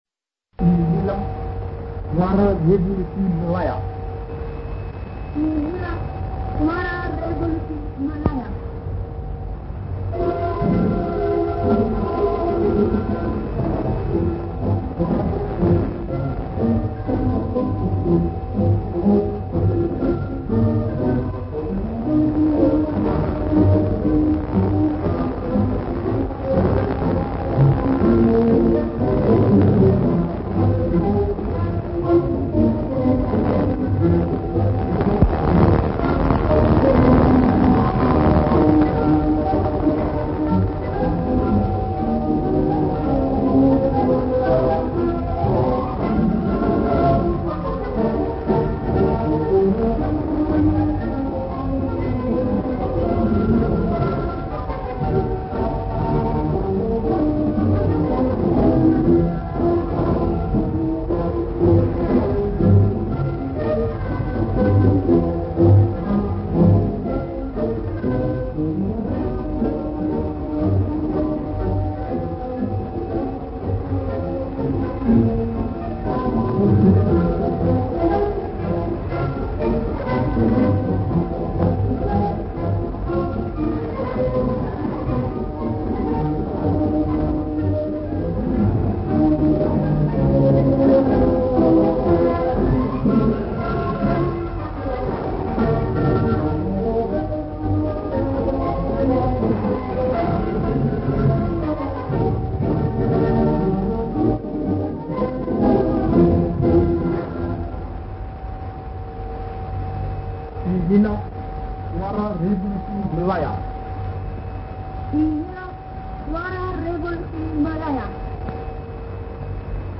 使用頻段短波